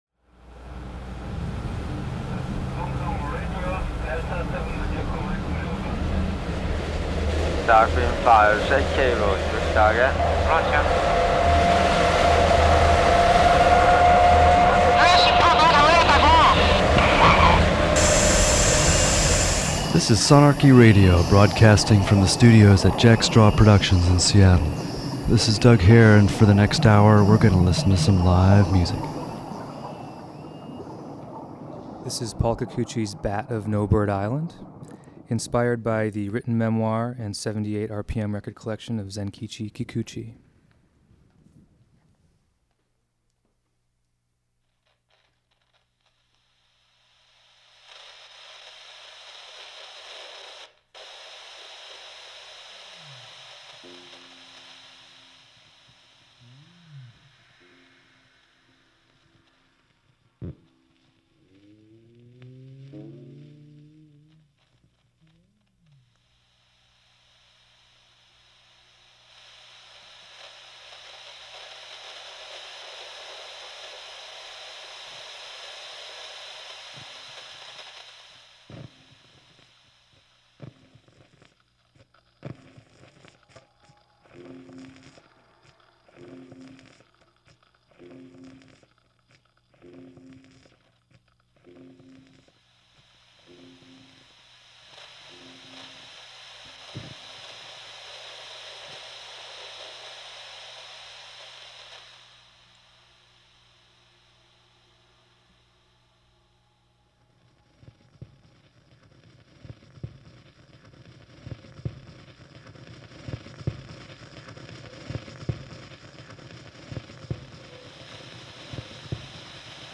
psychedelic hip-hop big band